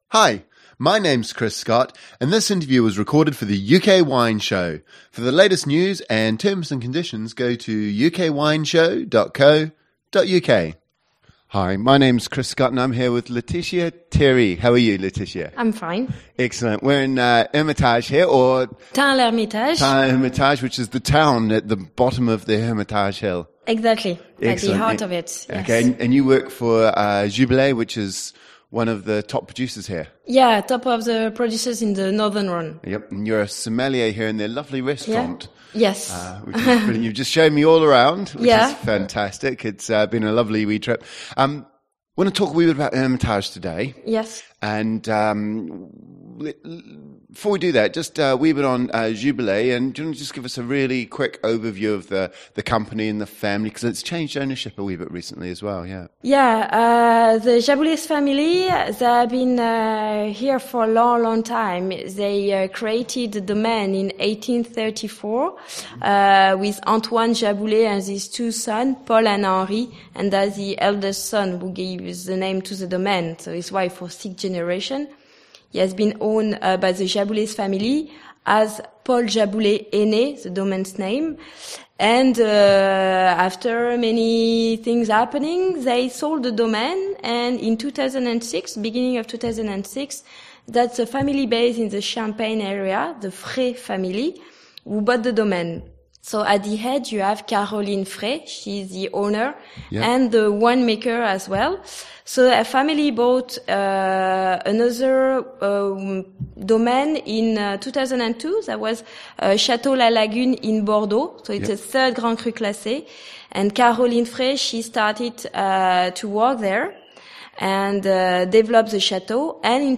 UK Wine Show » Listen to the interview only